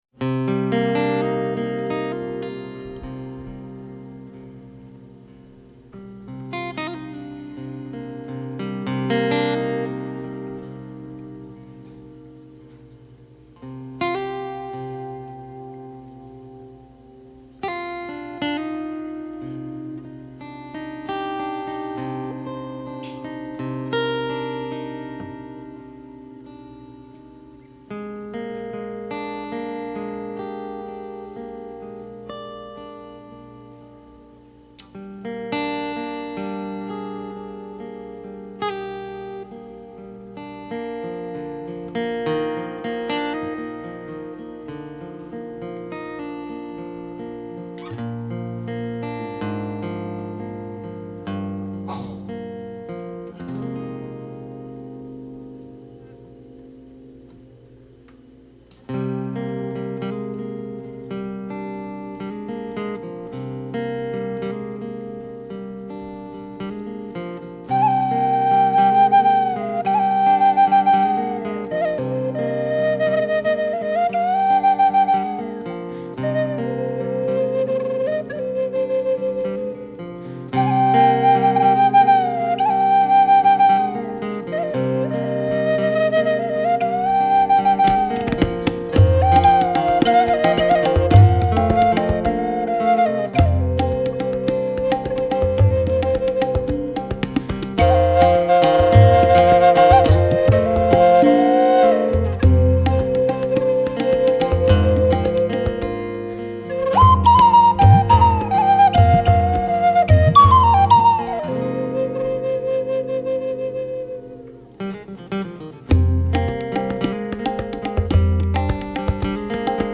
Live
Native American flutist